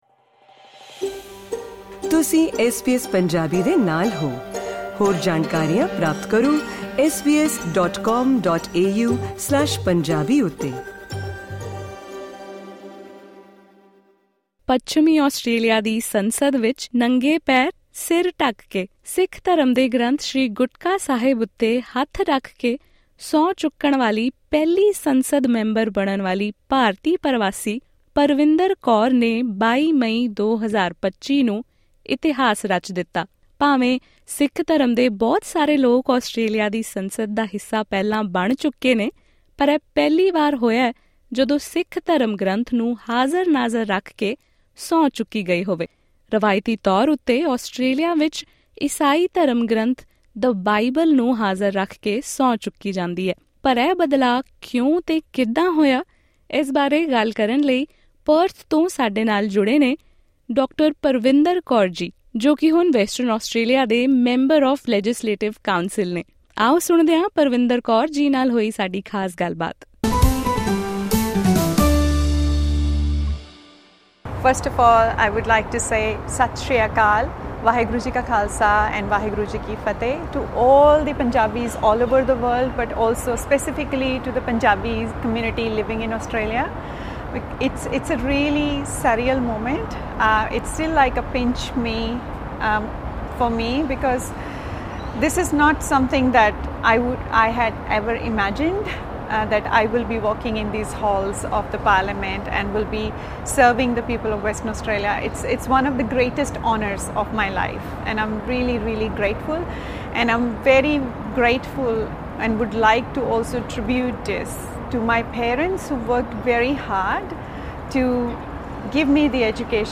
Listen to the full interview with her via this podcast: